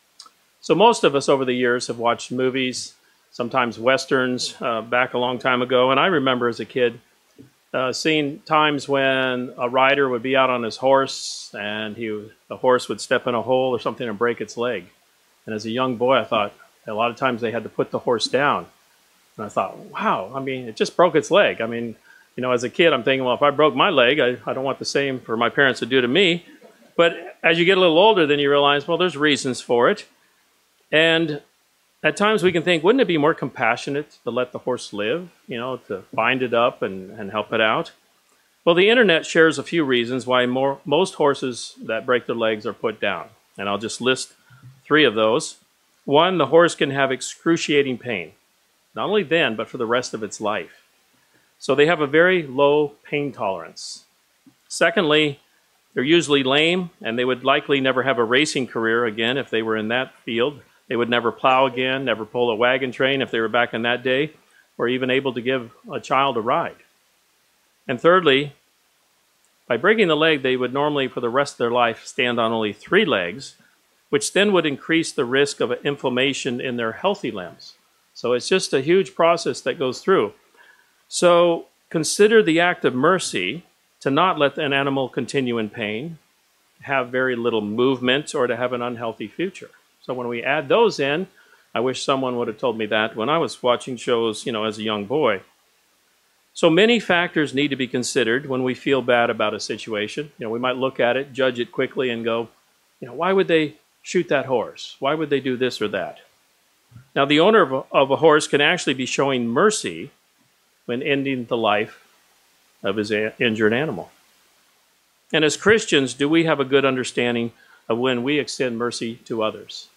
This sermon explores the amazing compassion and enduring mercy that our Heavenly Father and Jesus Christ are willing to extend to us.
Given in Tampa, FL